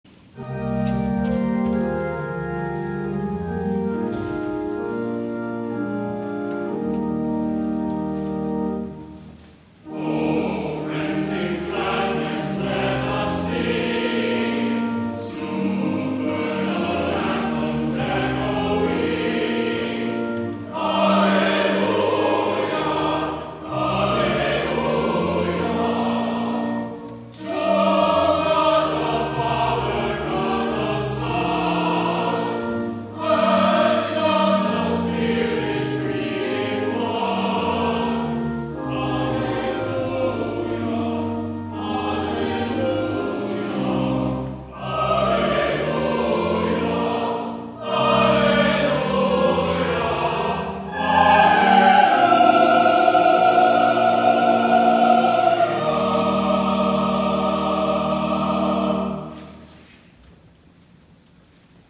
*THE CHORAL RESPONSE